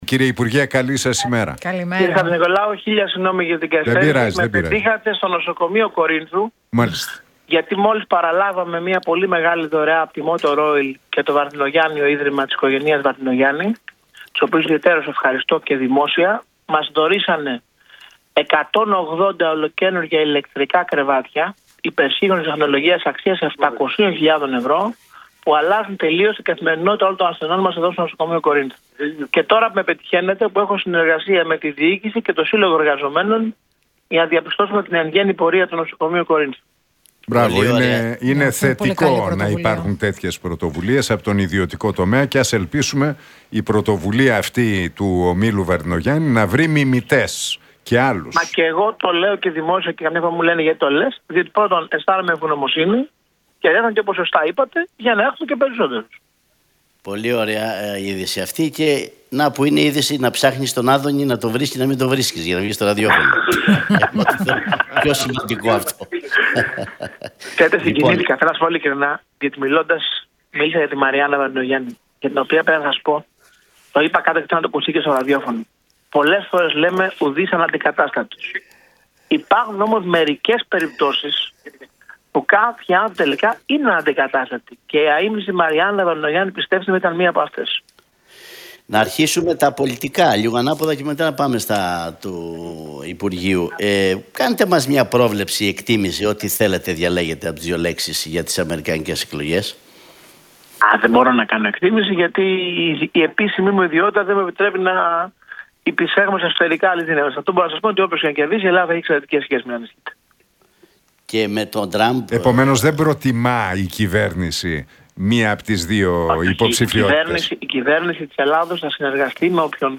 Για νέα μέτρα στο ΕΣΥ, τα νοσοκομεία, τα απογευματινά χειρουργεία αλλά και το πολιτικό τοπίο και τα ελληνοτουρκικά μίλησε ο υπουργός Υγείας, Άδωνις Γεωργιάδης